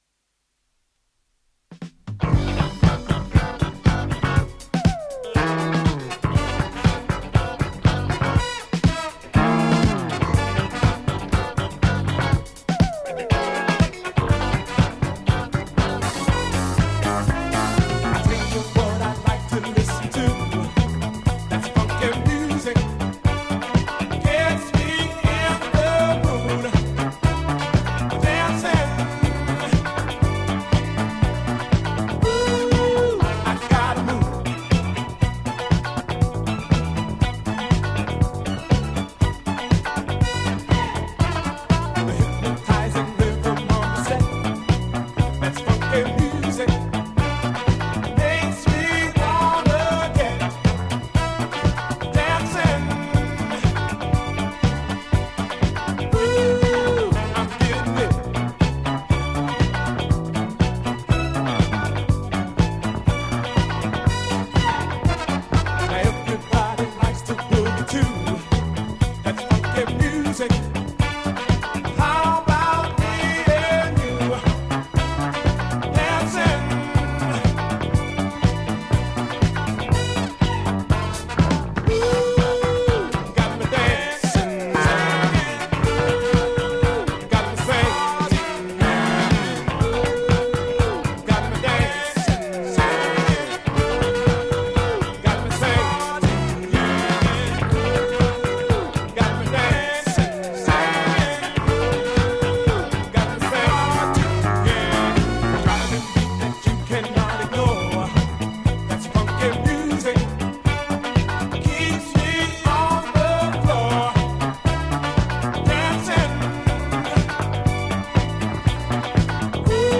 当前位置 > 首页 >音乐 >唱片 >俱乐部舞蹈 >房子
DANCE CLASSIC